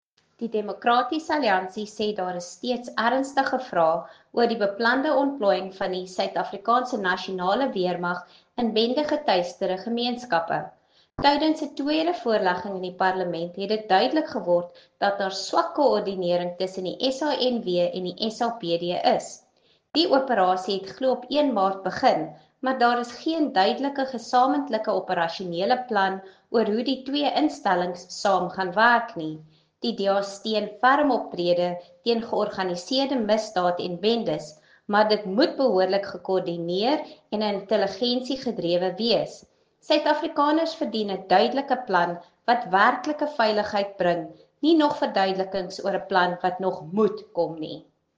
Afrikaans by Lisa Schickerling MP.